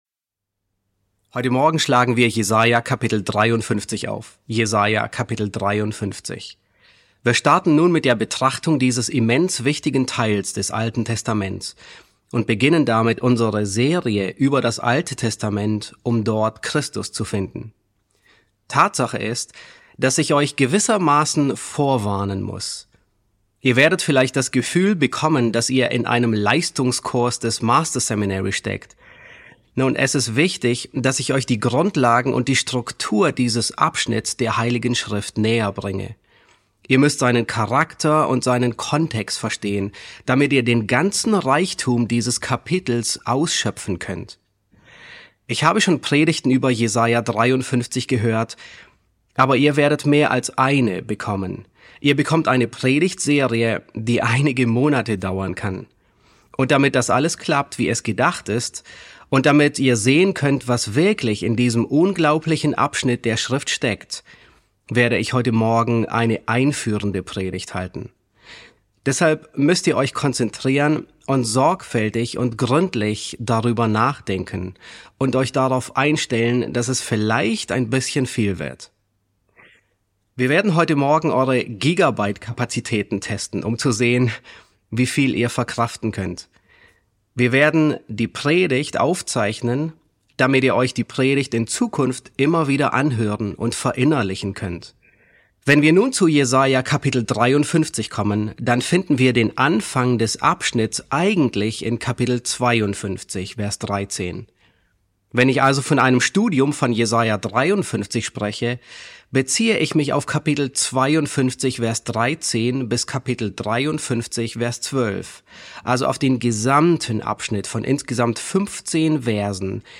E1 S4 | Der erstaunliche Knecht Jehovas ~ John MacArthur Predigten auf Deutsch Podcast